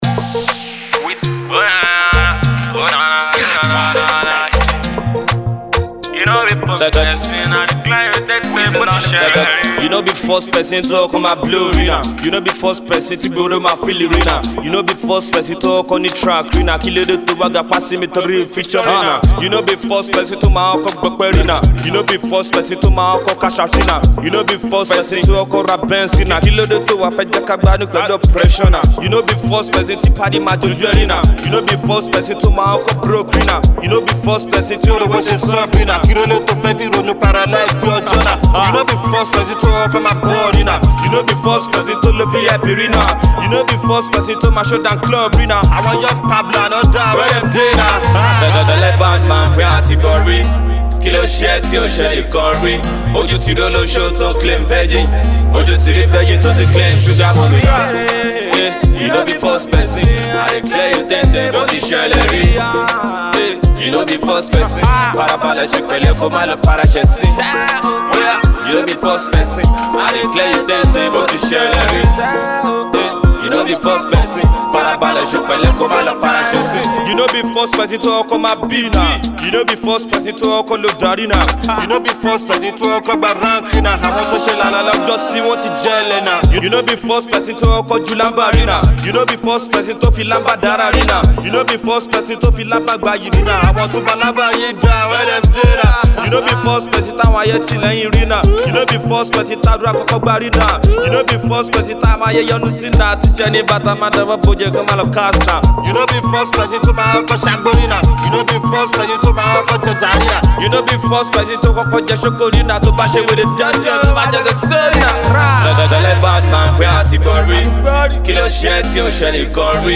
motivational and inspirational tune